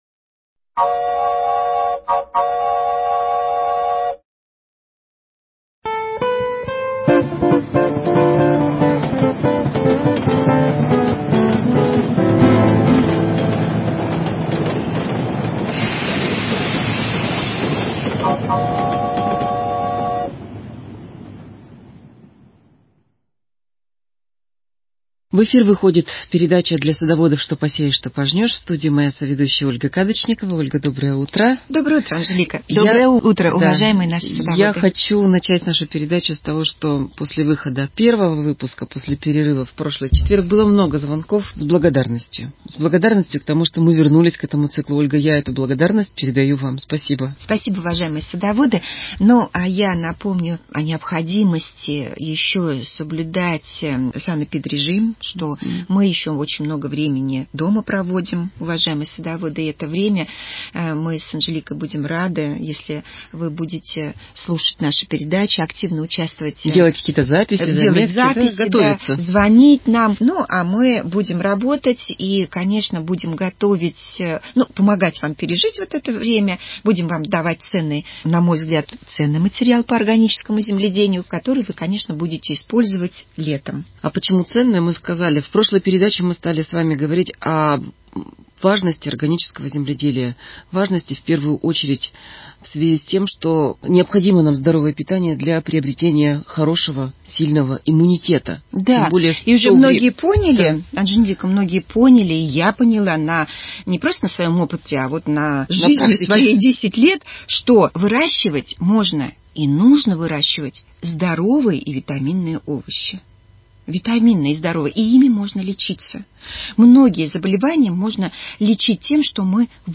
Передача для садоводов и огородников.